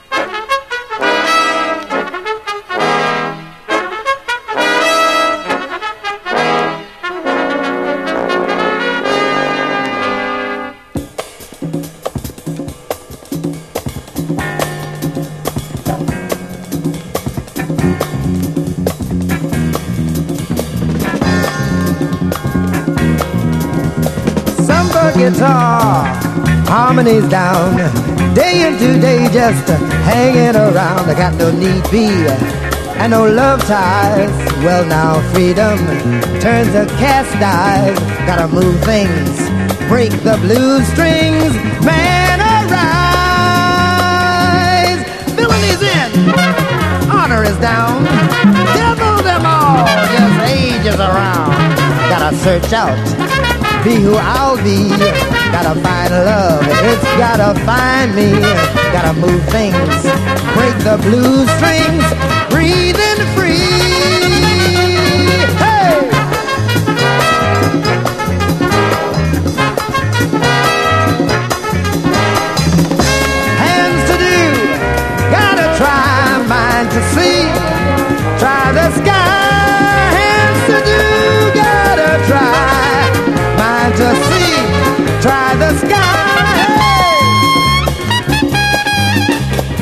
BREAKBEATS/HOUSE / BRAZILIAN HOUSE / CLUB JAZZ
使える曲ばかりのブラジリアン・トラック・オムニバス！